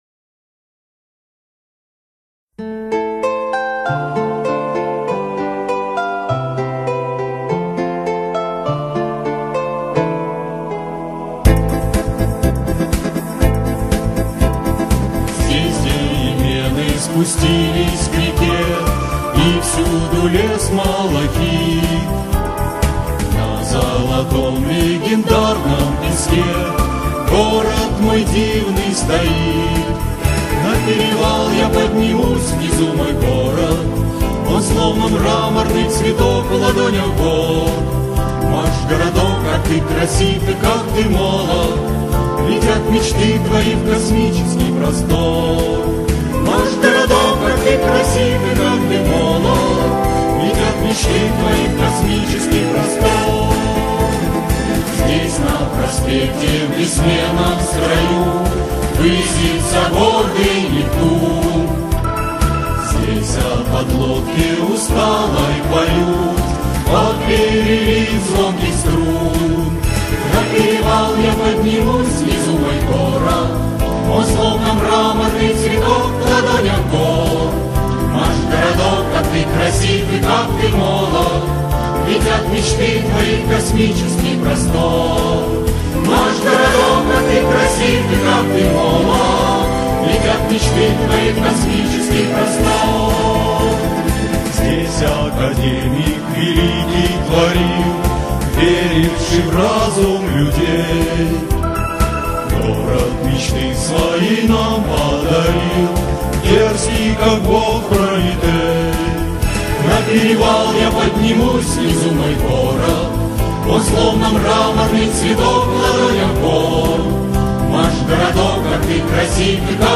12.-Песня-о-Машгородке-Академический-хор.mp3